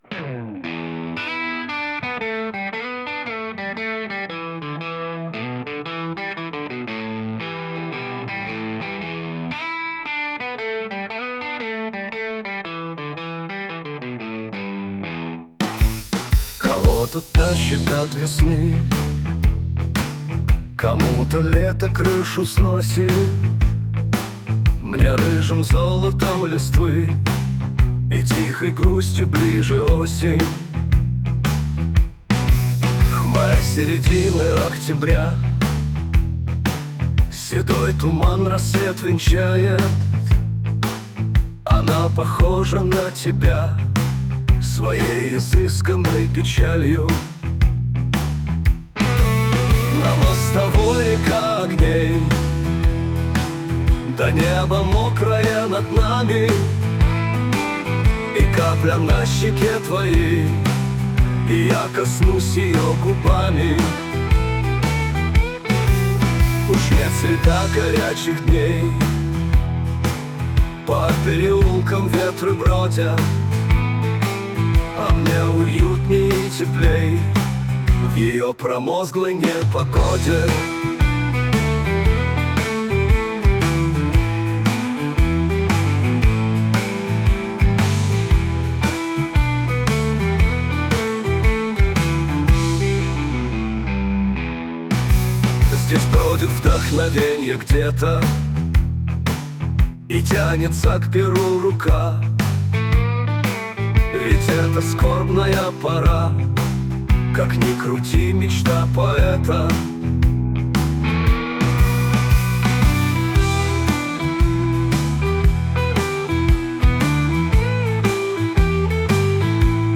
Ну и еще один блюзик: